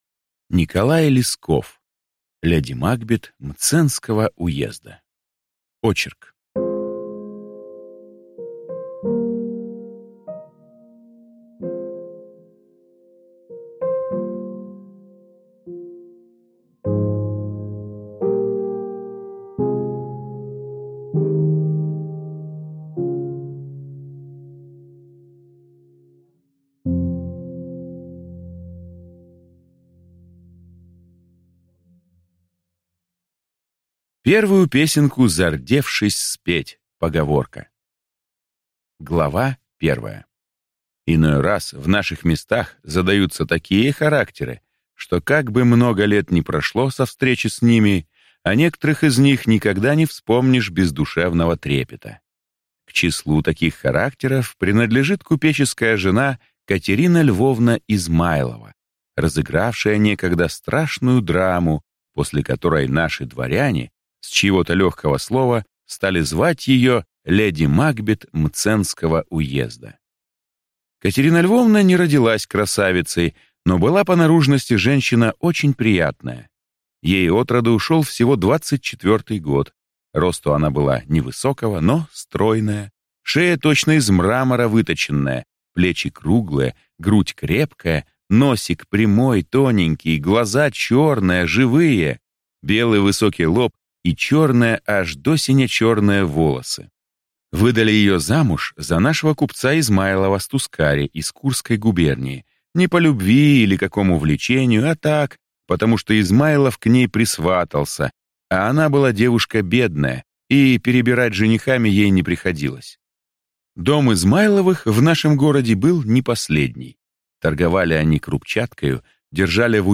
Аудиокнига Леди Макбет Мценского уезда | Библиотека аудиокниг